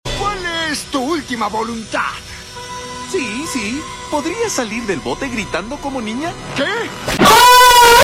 Salir Gritando Como Niña Sound Effect Download: Instant Soundboard Button